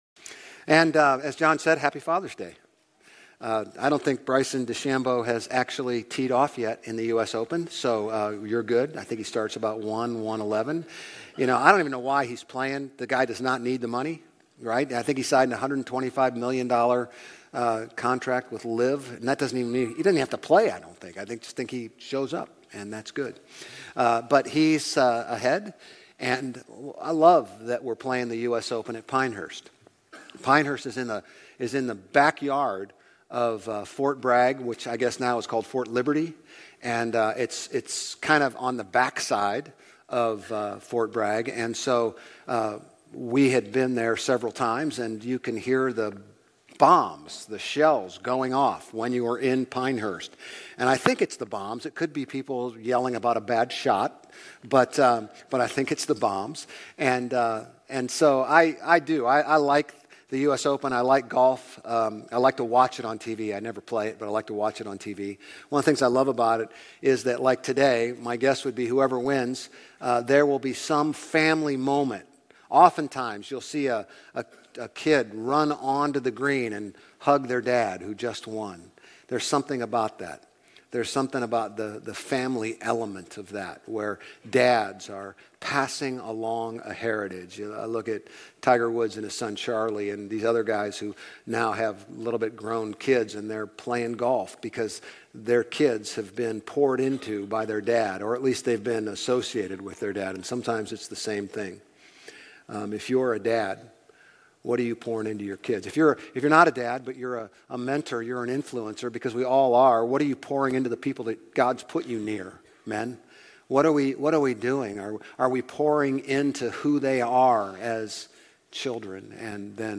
Grace Community Church Old Jacksonville Campus Sermons Galatians 6:11-18 Jun 17 2024 | 00:36:10 1x 00:00 / 00:36:10 Subscribe Share RSS Feed Share Link Embed